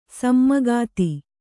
♪ sammagāti